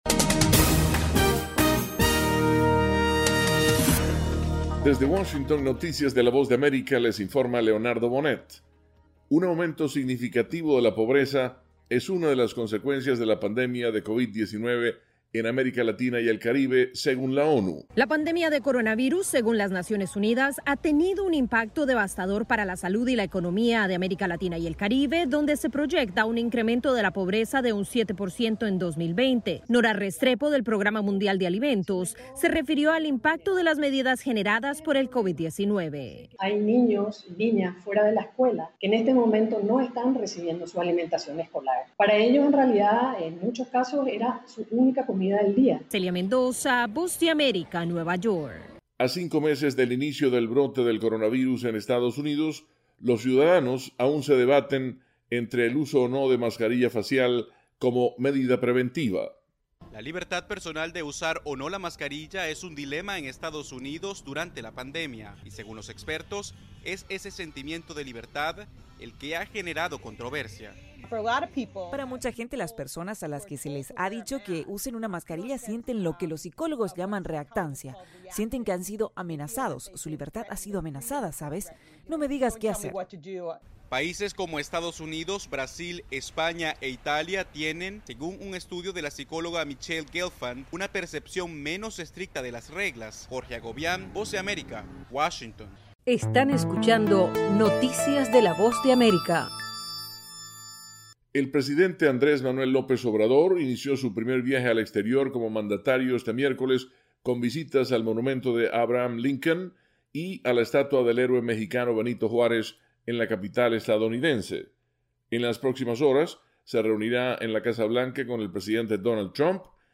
Segmento informativo de 3 minutos con noticias de Estados Unidos y el mundo.